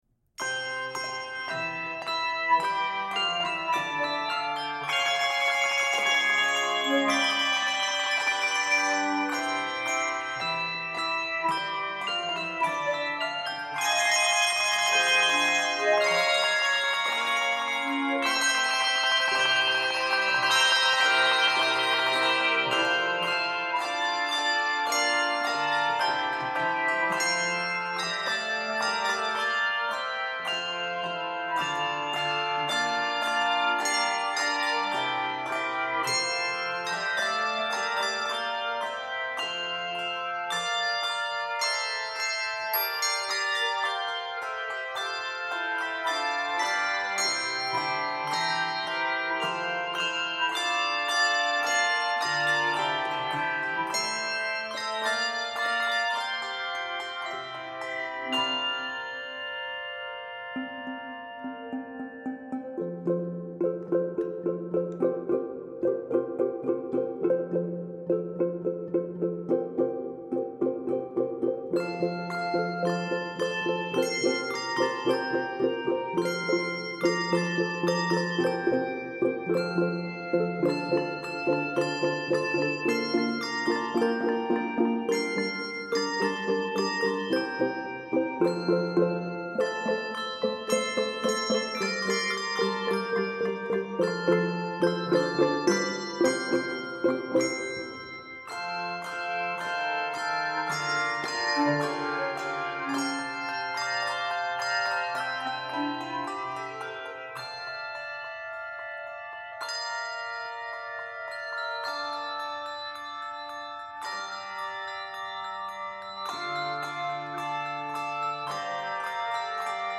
Keys of F Major and G Major.